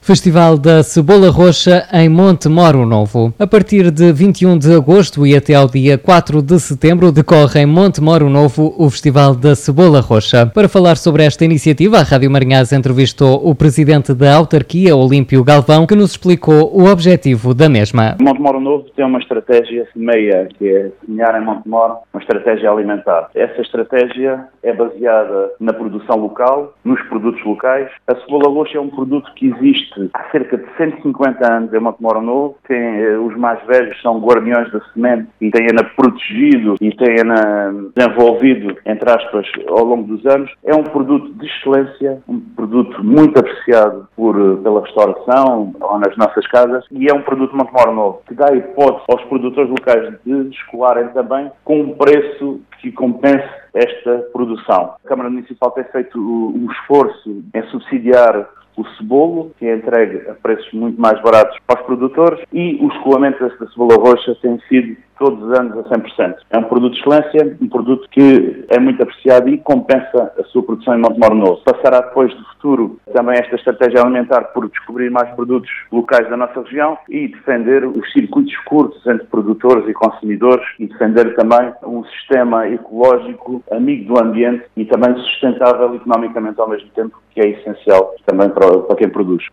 Escute, aqui, as declarações de Olímpio Galvão, Presidente da Câmara Municipal de Montemor-o-Novo, e fique a conhecer mais sobre a estratégia SMEA do Município: